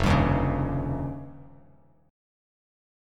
F7sus2#5 chord